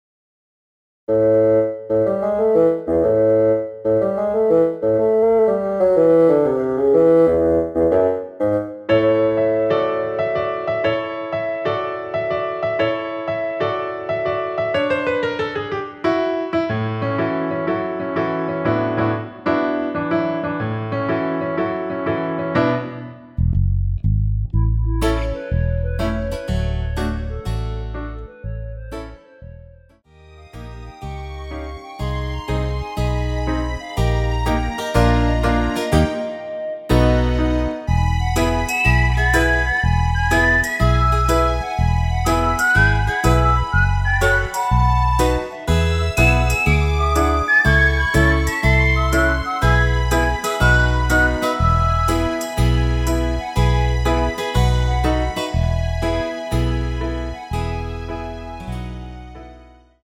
대부분의 여성분이 부르실 수 있는 키로 제작하였습니다.
원키에서(+4)올린 멜로디 포함된 MR입니다.(미리듣기 확인)
노래방에서 노래를 부르실때 노래 부분에 가이드 멜로디가 따라 나와서
앞부분30초, 뒷부분30초씩 편집해서 올려 드리고 있습니다.